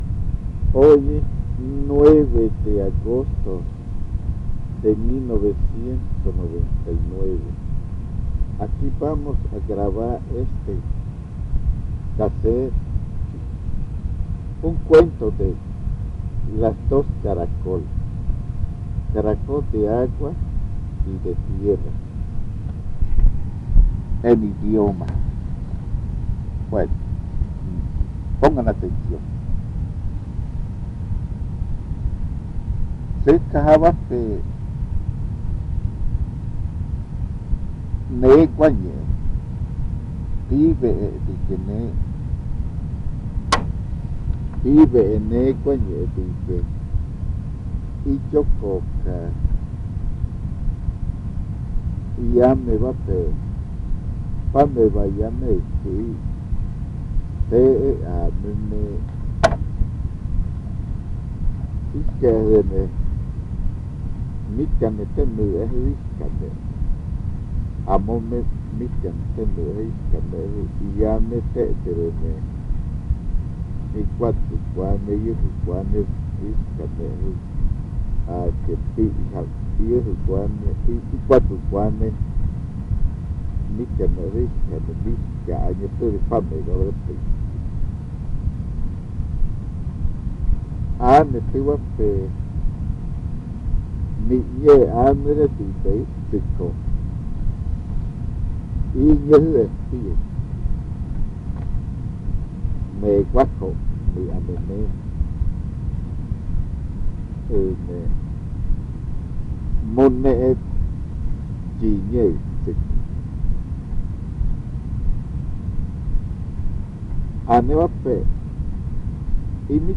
El audio incluye los lados A y B del casete.